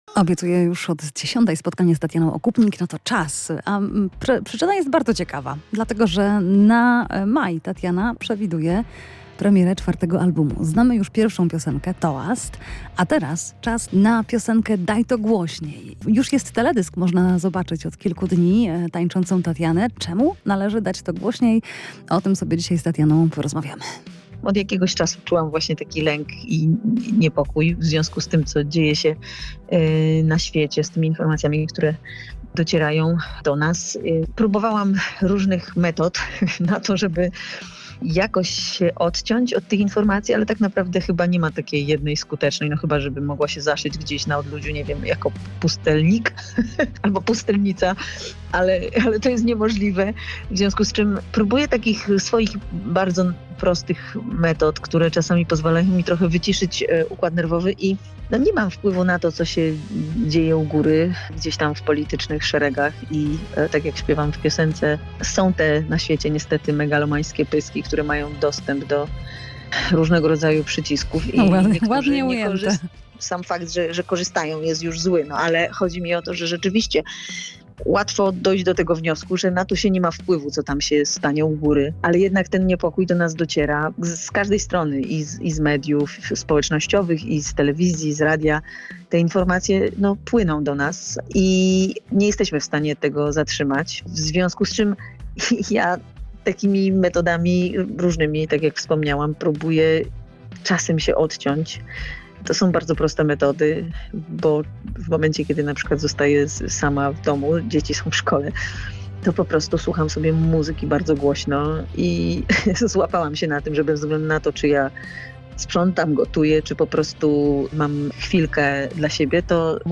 Tatiana Okupnik i jej głośna piosenka o wyciszeniu [POSŁUCHAJ ROZMOWY]